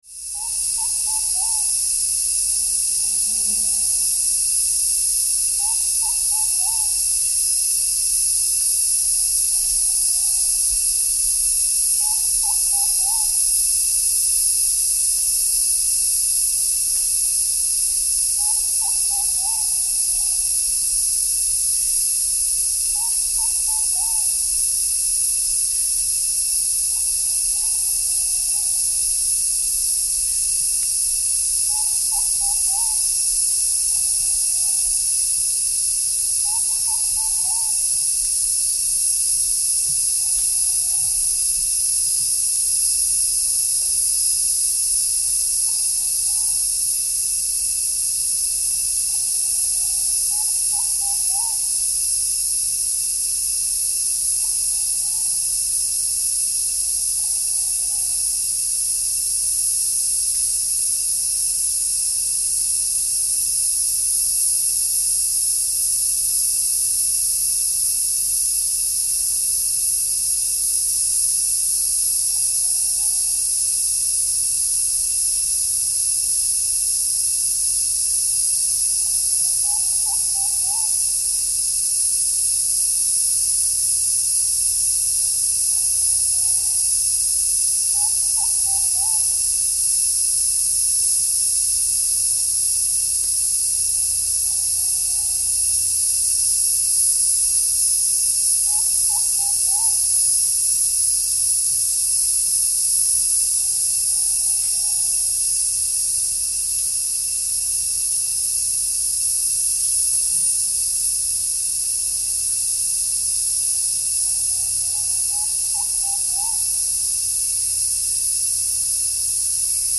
Tropical forest at midday
Typical midday sounds at La Selva Biological Reserve, Costa Rica. Loud cicadas dominate with a few persistent singers (Columba nigrirostris).